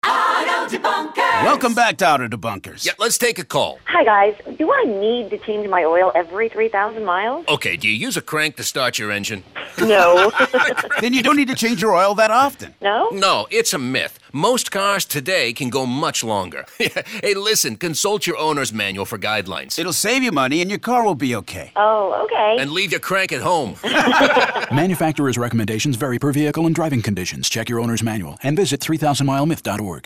PSA